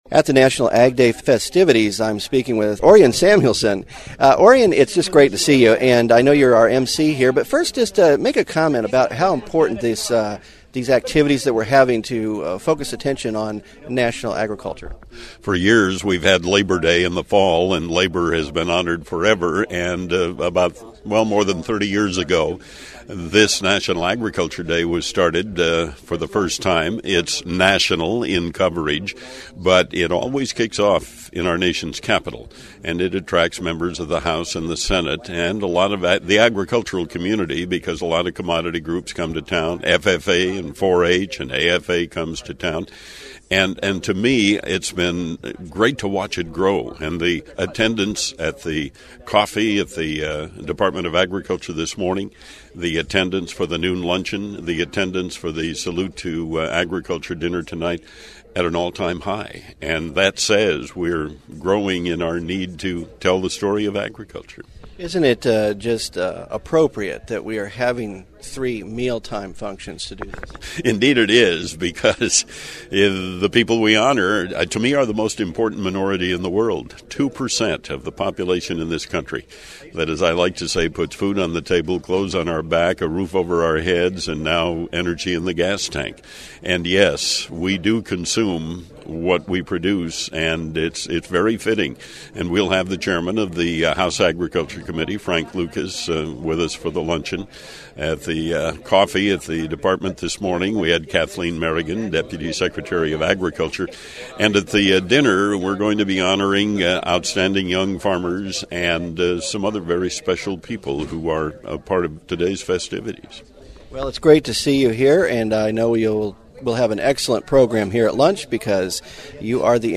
I spoke with Orion before the program got started to get his thoughts on Ag Day and learn what we’ll be doing this celebrate American agriculture day.
Orion Samuelson Interview